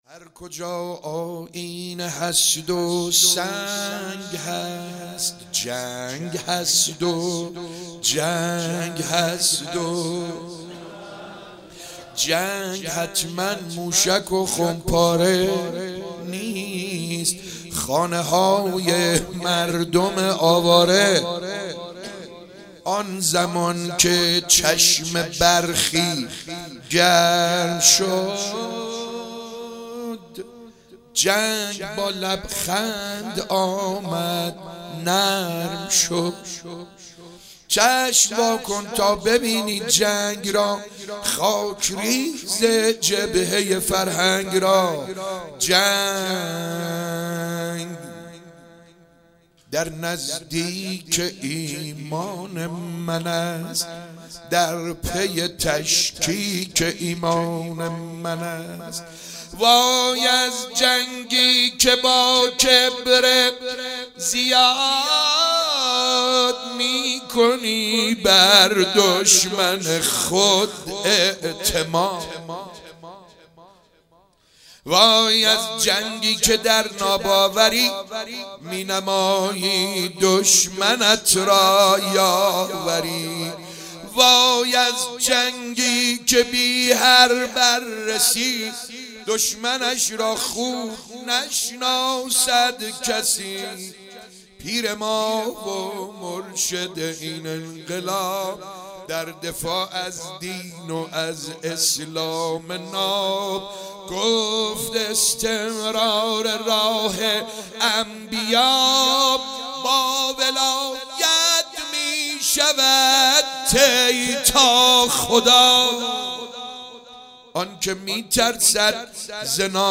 شب 25 ماه مبارک رمضان 96 - شعرخوانی - هر کجا آیینه هست و سنگ هست